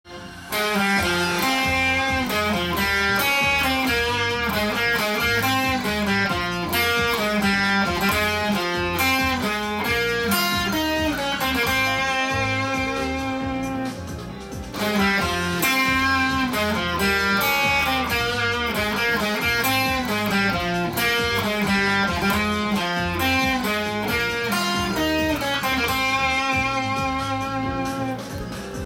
音源にあわせて譜面通り弾いてみました